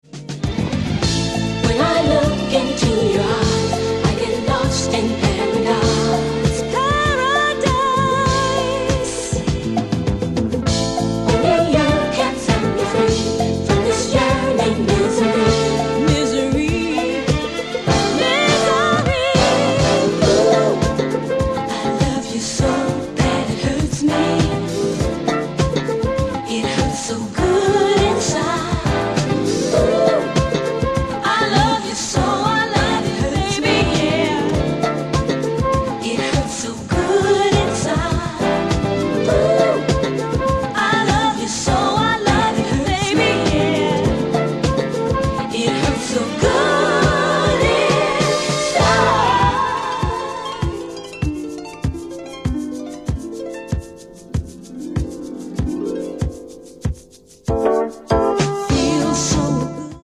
Quality re-issue of 2 must have early disco giants